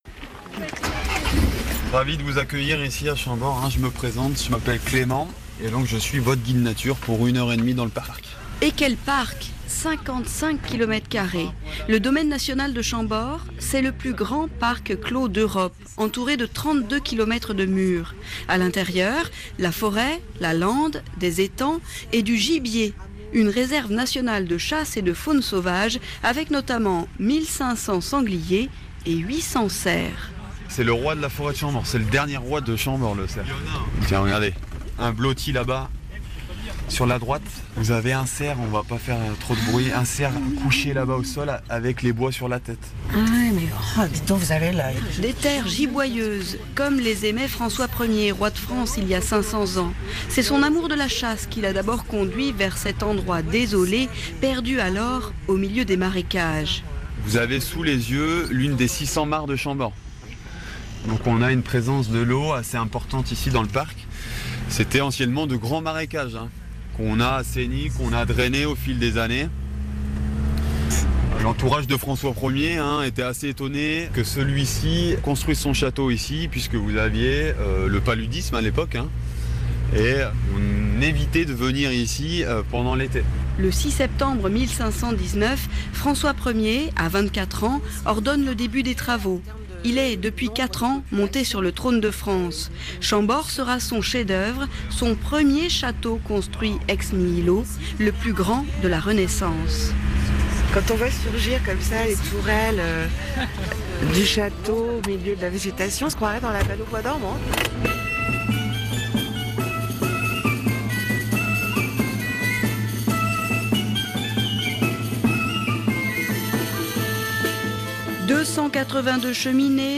C'est sans doute l'un des plus célèbres et des plus beaux châteaux de France. Chambord, voulu par le roi François Ier, fête cette année ses 500 ans. Dans ce grand reportage réalisé par nos confrères de RFI